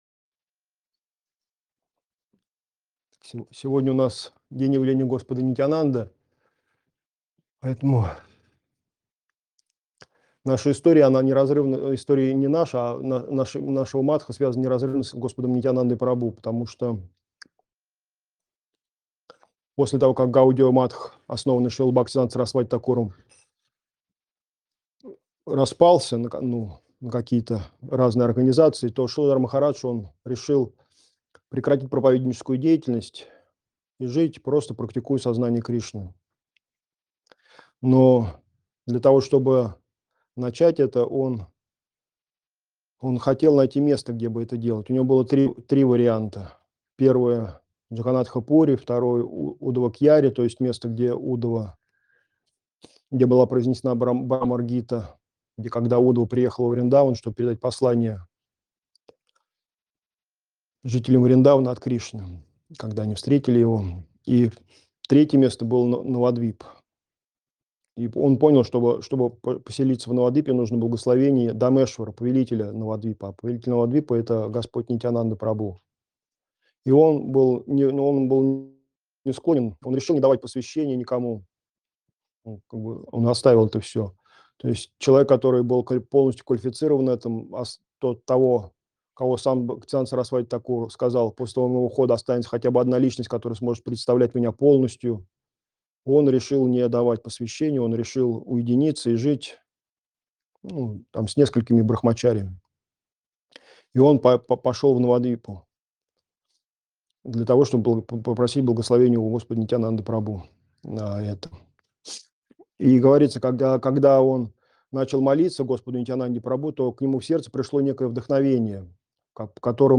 Москва, Кисельный
Лекции полностью